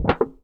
metal_tin_impacts_movement_wobble_01.wav